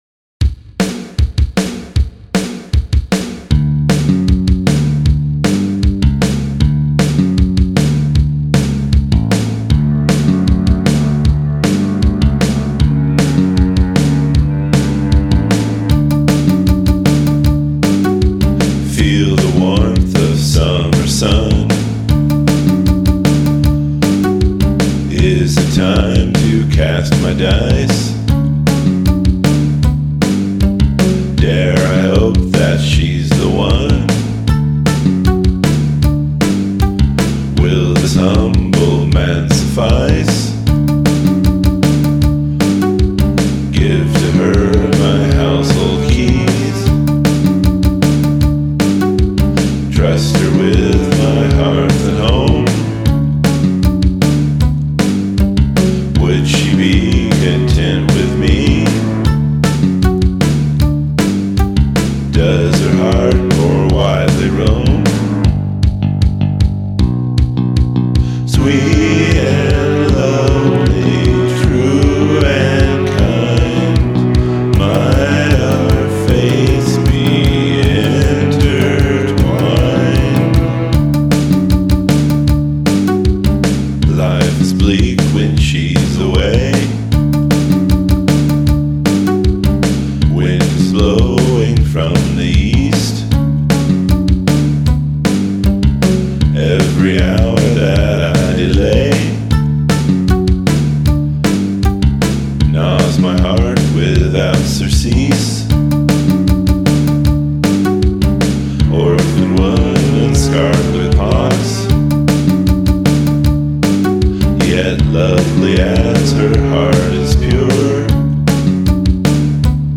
The koto sound is cool.